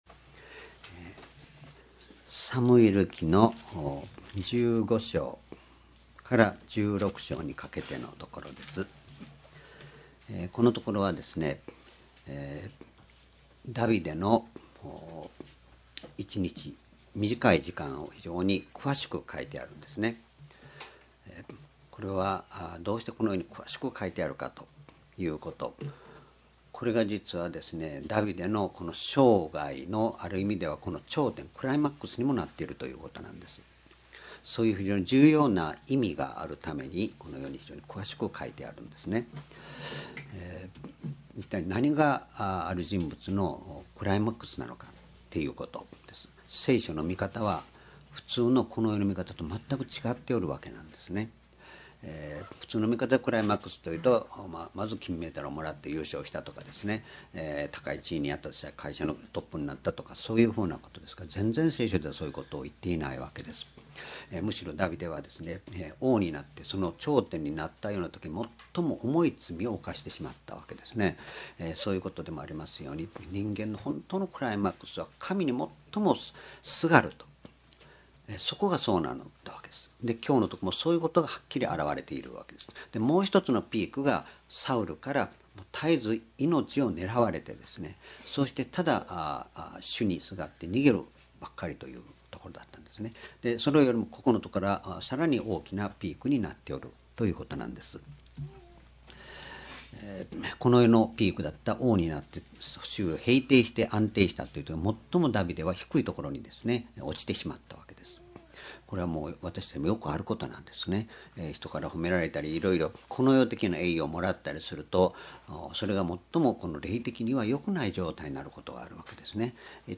主日礼拝日時 ２０１４年４月６日（日曜日） 聖書講話箇所 サムエル記下15章24～16章１－１４ 「闇の中における神の御計画」 ※視聴できない場合は をクリックしてください。